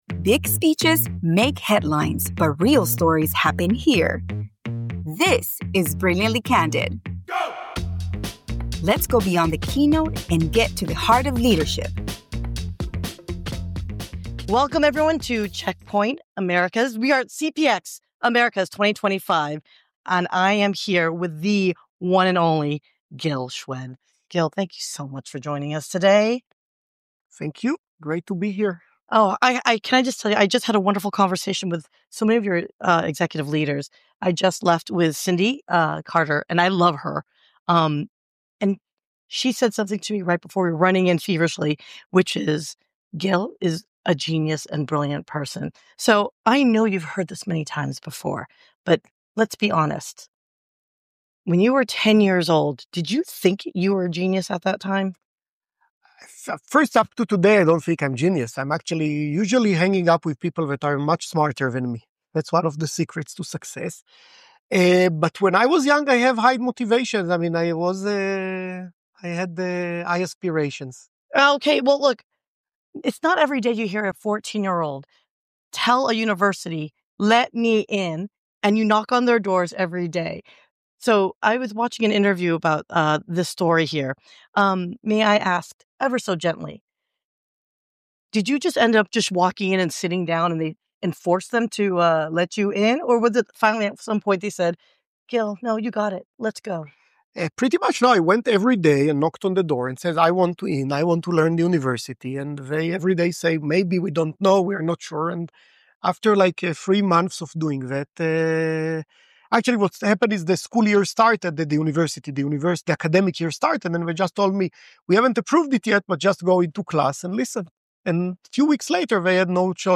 In this thought-provoking (and laugh-out-loud) episode, we sit down with Gil Shwed, Chairman of the Board and Co-Founder of Check Point Software, for a candid conversation that blends business insight, human connection, and just a dash of hot dog controversy.